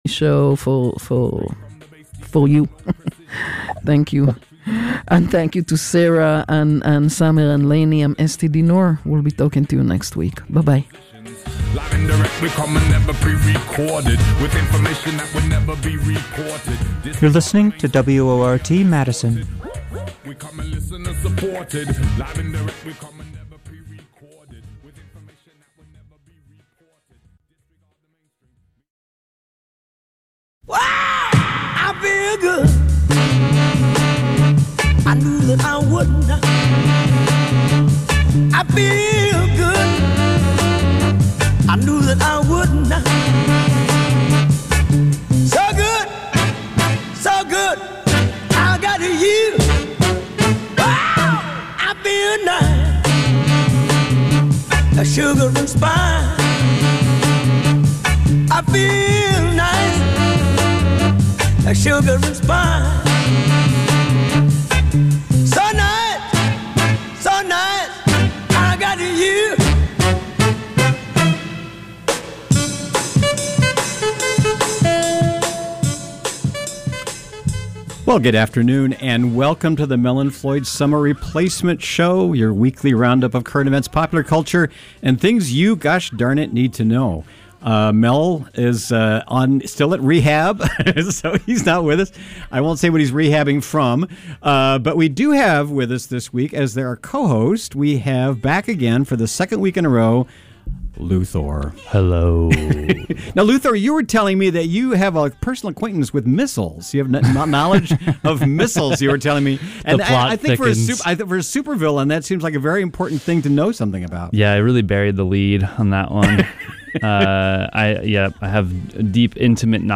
Two wacky funny guys broadcast a hilarious blend of political commentary, weird news, and stand up comedy.